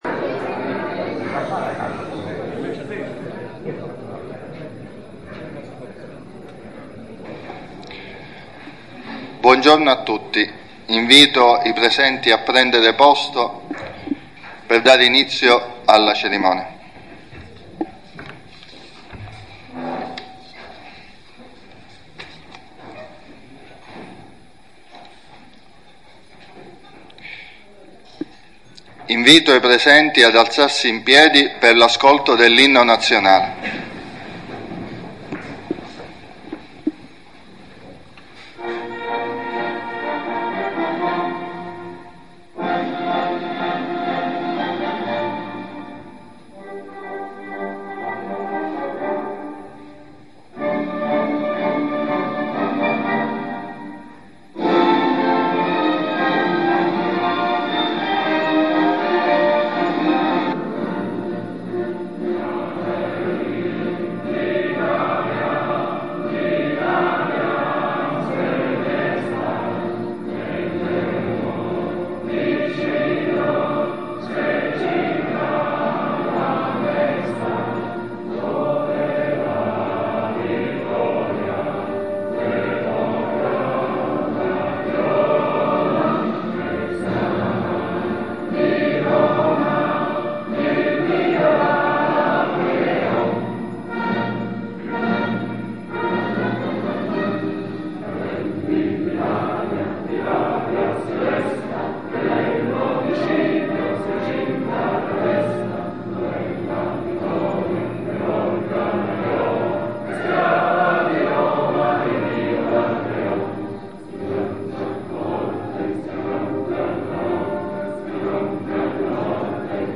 AUDIO della COMMEMORAZIONE DELLA MEMORIA (Nella Sala delle Capriate del Comune di Cefalù)